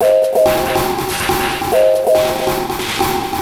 E Kit 08.wav